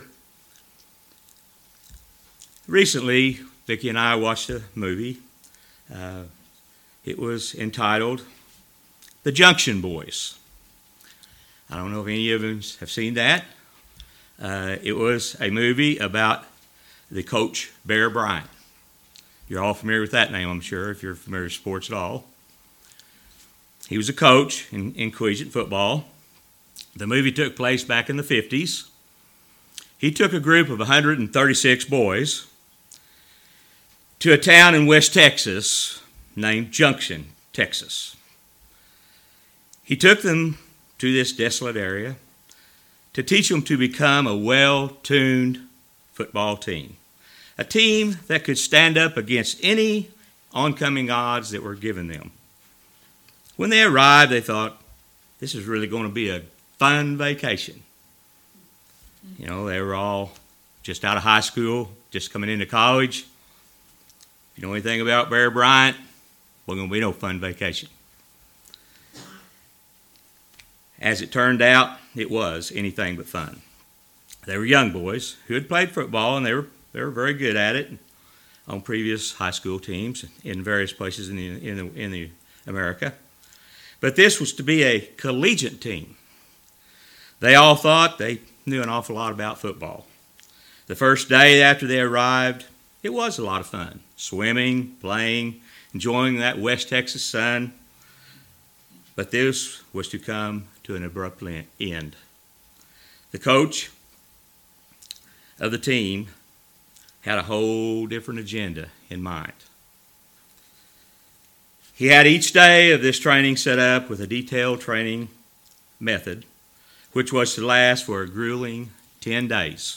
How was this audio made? Given in Knoxville, TN